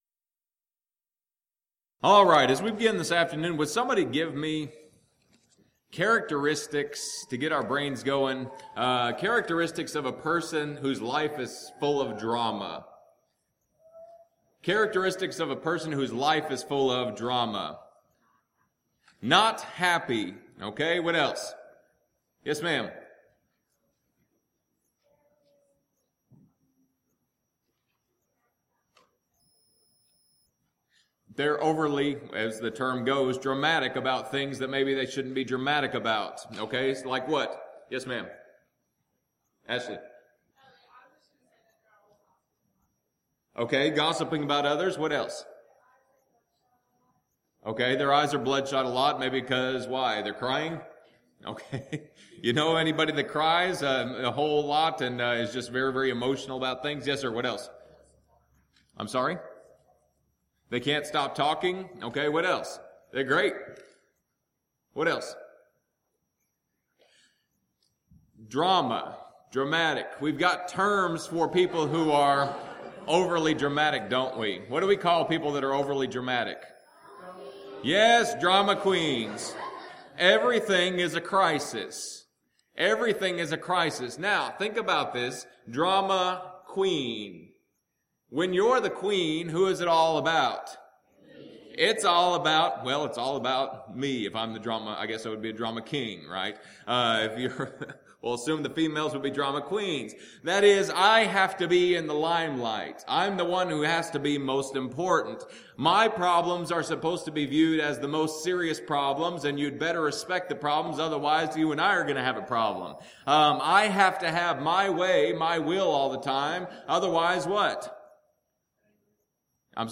Alternate File Link File Details: Series: Discipleship University Event: Discipleship University 2013 Theme/Title: Dead or Alive: Lessons about faith from a man who served Jesus.
If you would like to order audio or video copies of this lecture, please contact our office and reference asset: 2013DiscipleshipU10 Report Problems